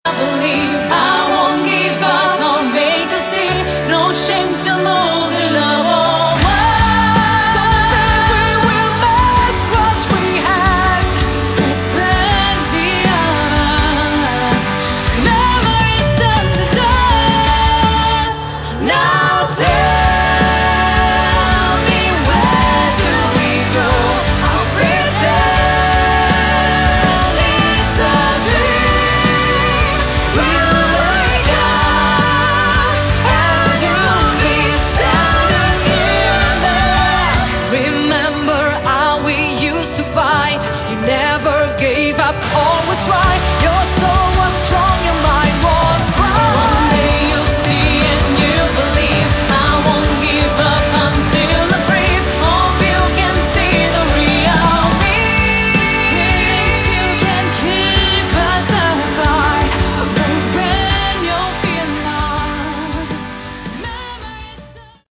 Category: Melodic Metal
vocals
guitars
bass
drums
keyboards